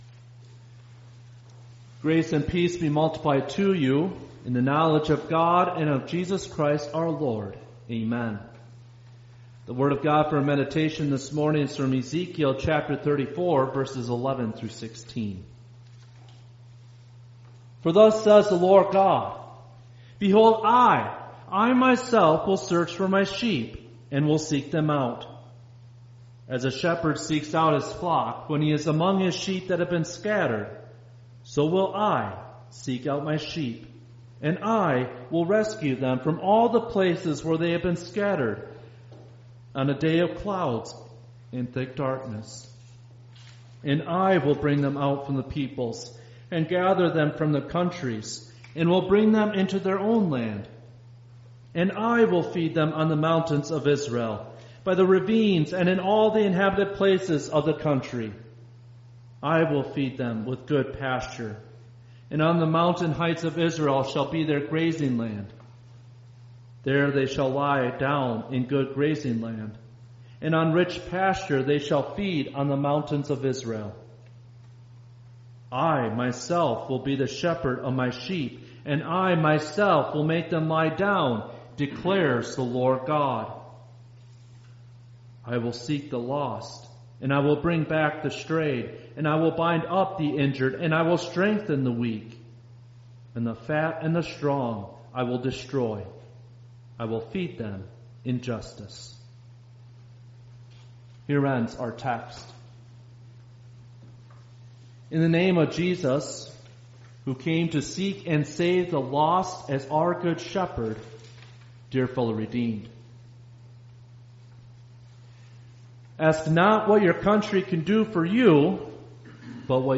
Misericodias_Domini_Sunday_Service_May_4_2025.mp3